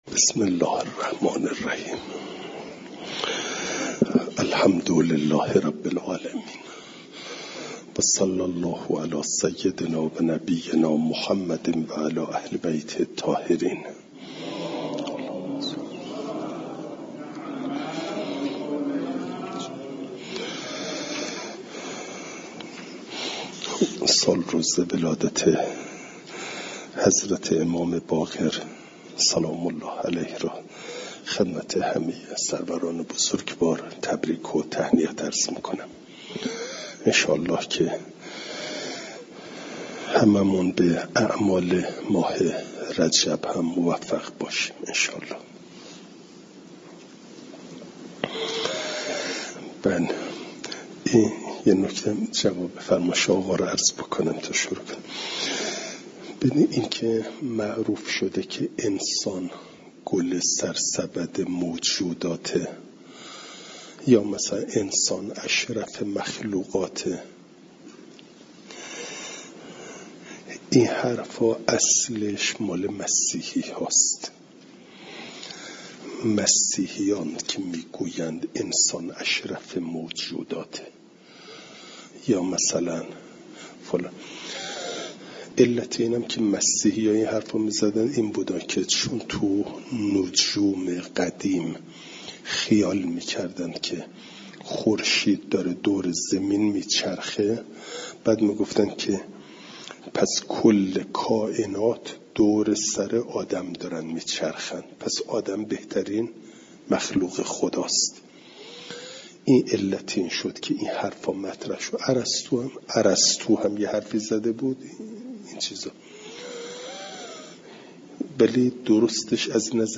فایل صوتی جلسه صد و شصت و یکم درس تفسیر مجمع البیان